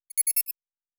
pgs/Assets/Audio/Sci-Fi Sounds/Interface/Error 11.wav at 7452e70b8c5ad2f7daae623e1a952eb18c9caab4
Error 11.wav